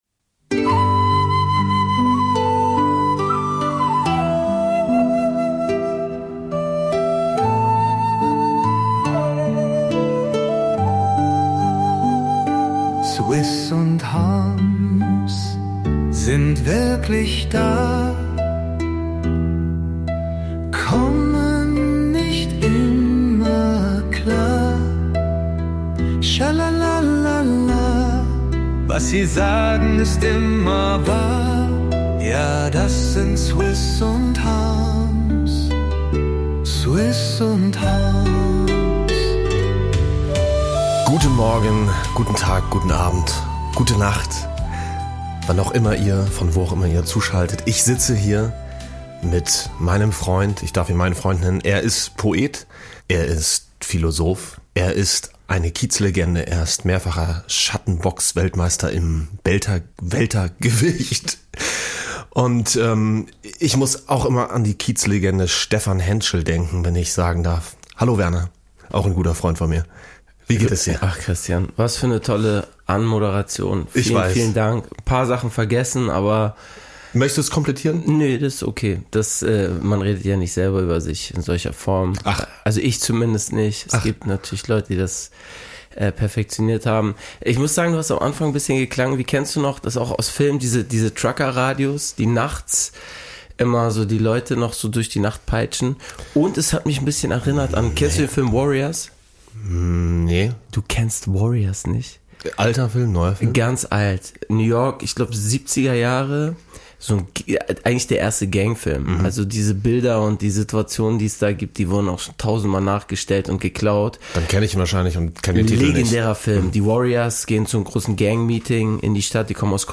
Die Musiker Swiss und Chris Harms verabreden sich jeden zweiten Donnerstag zu einem Gespräch über Gott und die Welt. Mal ist der eine in Mexiko, mal der andere irgendwo in den Bergen.